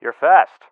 LSO-Fast_Loud.ogg